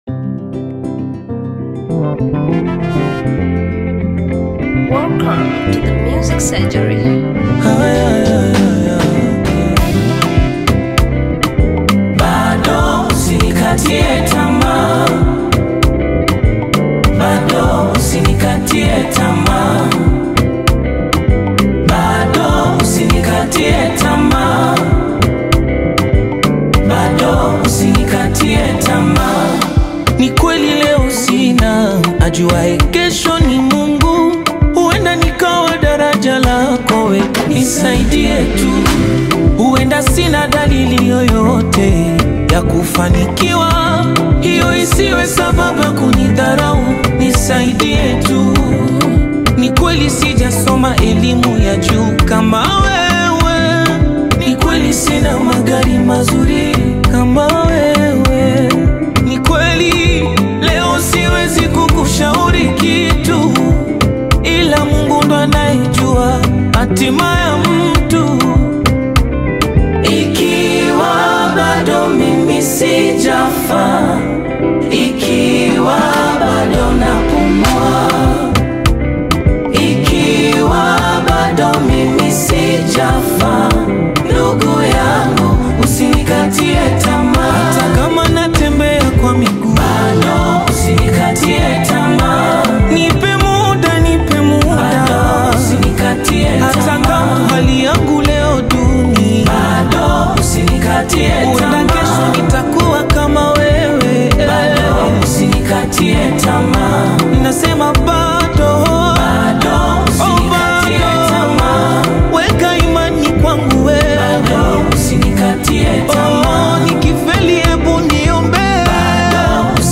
Tanzanian gospel music
strong vocal delivery
heartfelt gospel song